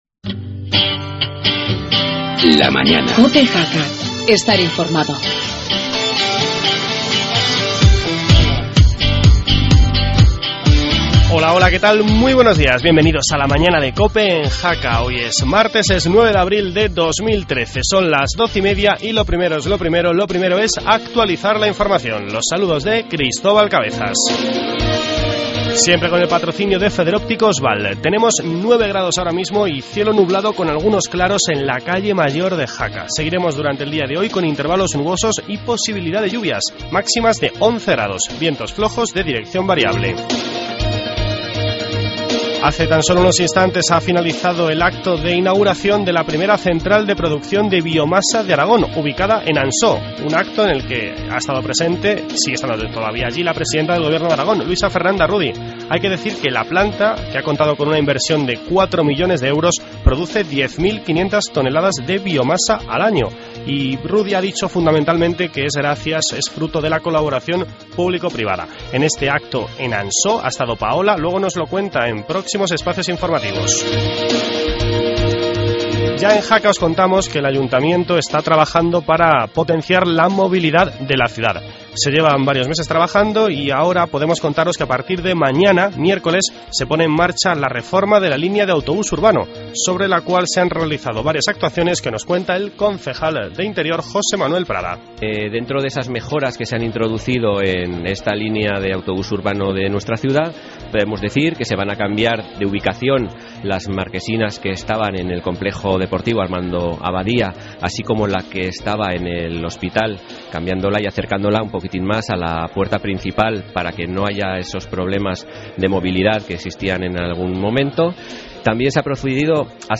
Tertulia municipal